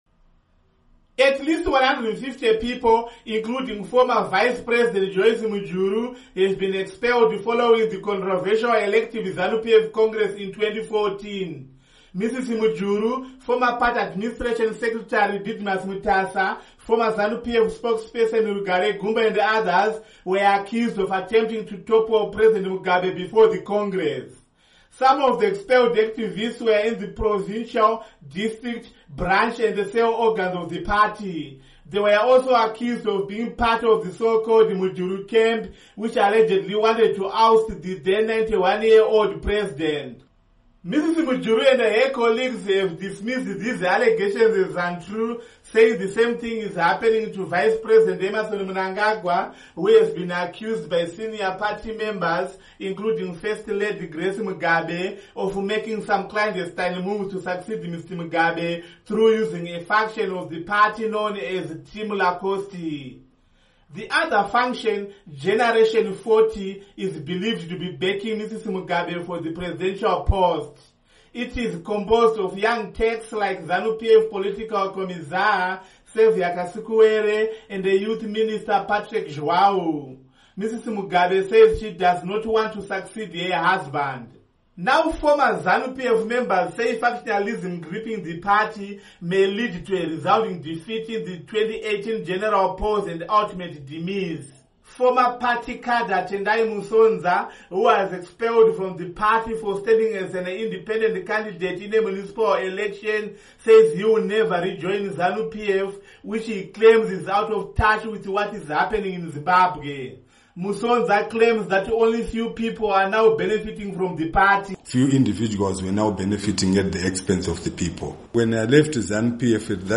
CHINHOYI —